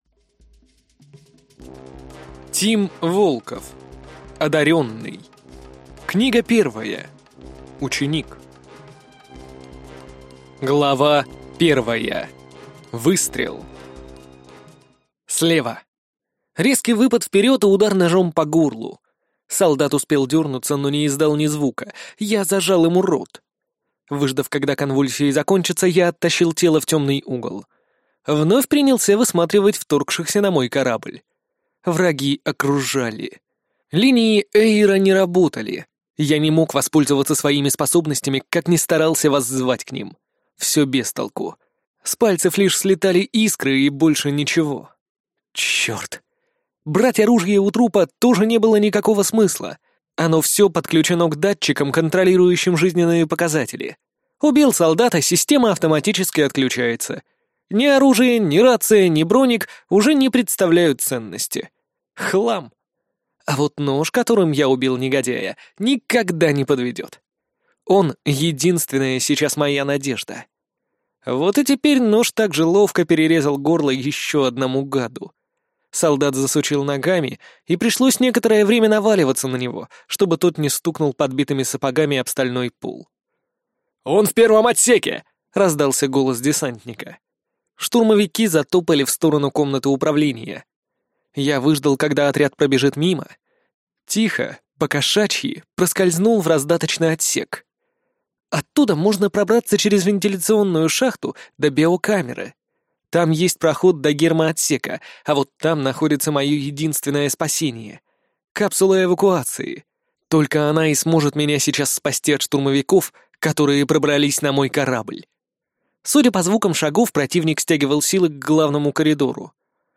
Аудиокнига Одарённый. Книга 1. Ученик | Библиотека аудиокниг